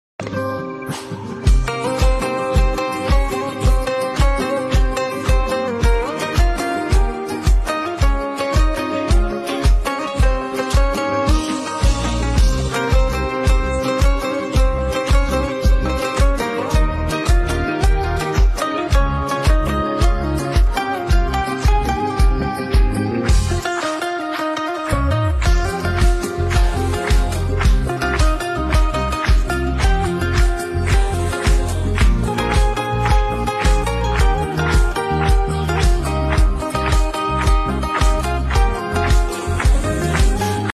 آهنگ بی کلام